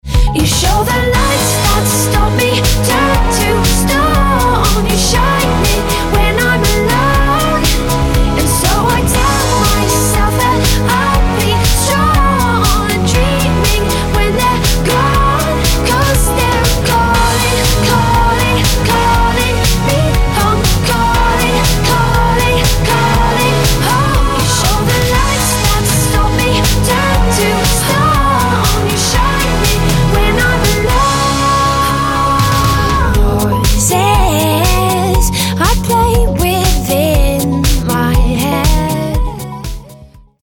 23-ročná anglická speváčka